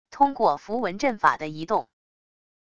通过符文阵法的移动wav音频